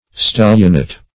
Stellionate \Stel"lion*ate\, n. [L. stellionatus cozenage,